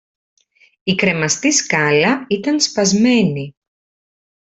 female_ref.mp3